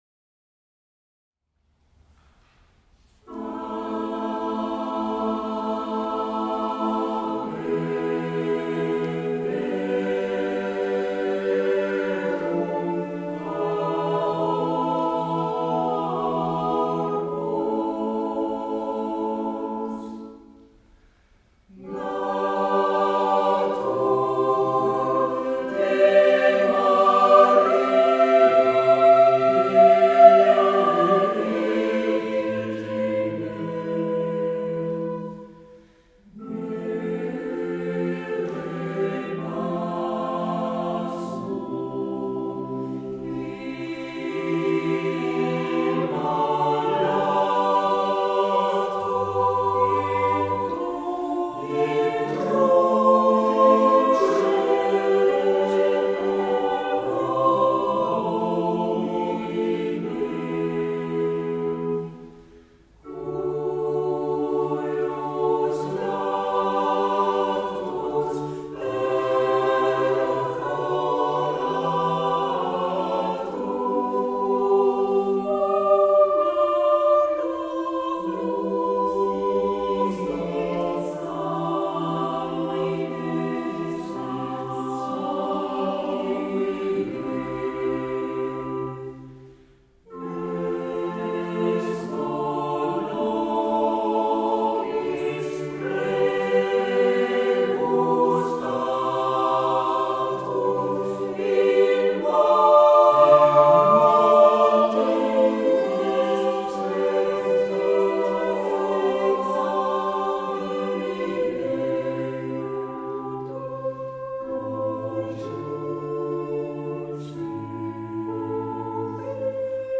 Baroque allemand et anglais pour choeur et orgue - Polyphonie moderne a capella
transposé en la mineur avec l'orgue au tempérament égal de Rolle et à 440Hz,